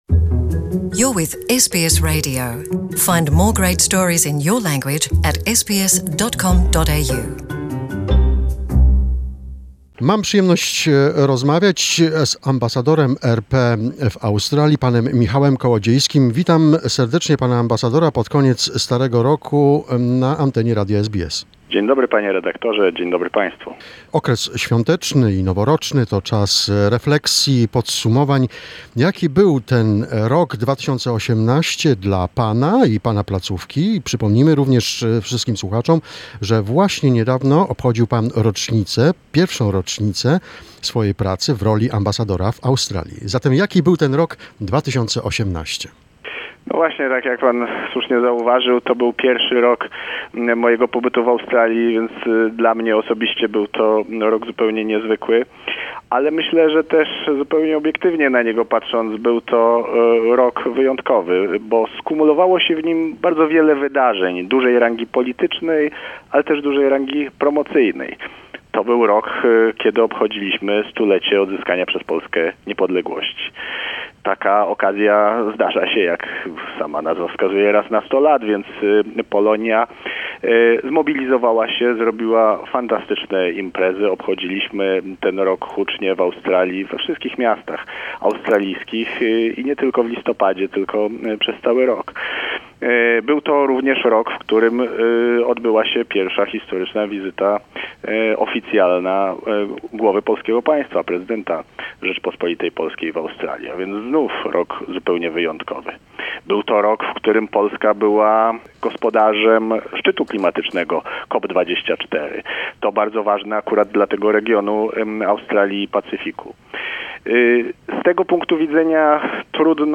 An interview with the Polish Ambassador in Australia Mr Michał Kołodziejski.